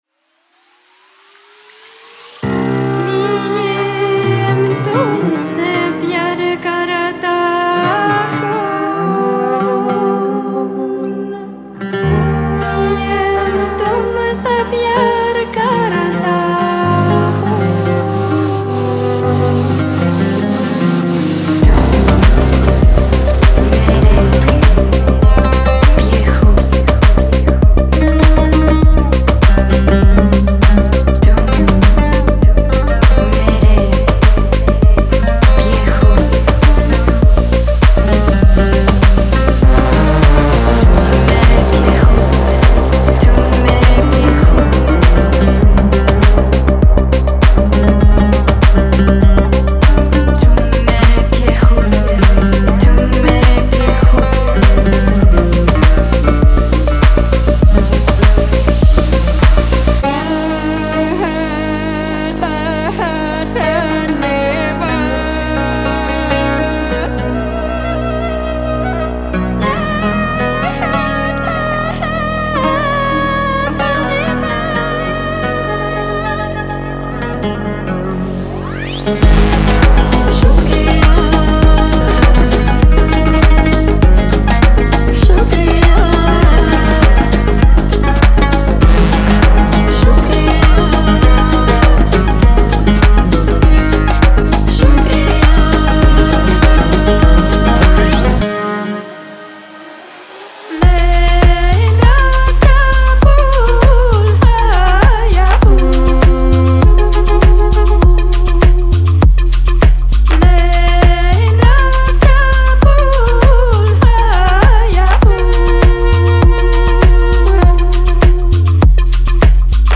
地鳴りのように唸るベース — 地中からの鼓動を思わせるディープな低域。
マントラボーカル — 言葉を超えた祈り、古代のチャント、そして幽玄なマントラ。
FX＆アトモスフィア — 寺院の残響のようなリバーブと倍音が舞うサウンドテクスチャ。
本格的な民族楽器と打楽器 — ウード、サズ、フルート、パーカッションがフラクタル状のグルーヴを織り上げます。
哀愁漂うヴァイオリンライン
サンスクリット語、チベット語、ヴェーダ語による女性声の重唱
ドーム状の寺院で録音されたコール＆レスポンス（自然な5秒の残響付き）
Genre:Melodic Techno
100 BPM